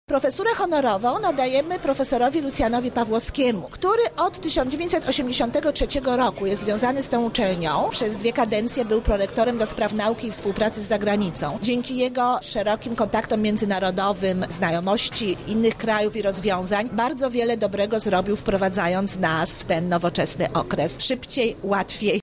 Tytuł otrzymał na obchodach święta uczelni.